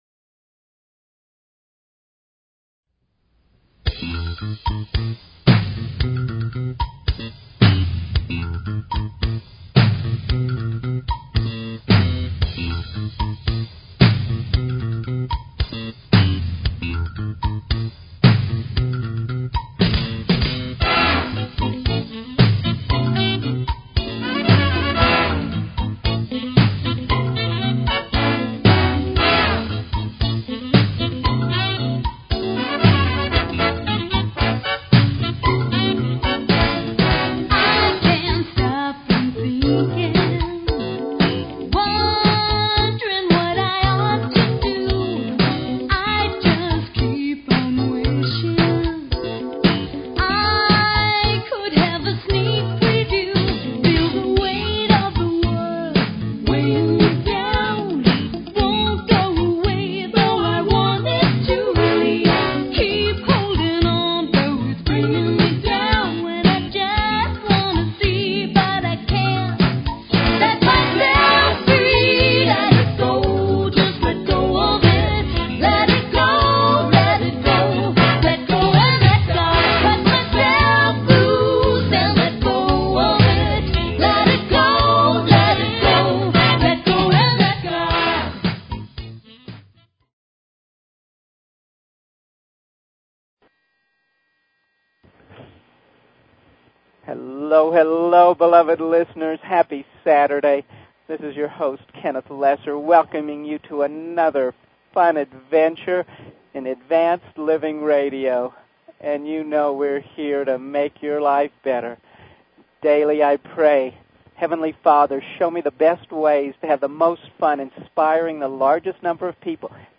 Talk Show Advanced Living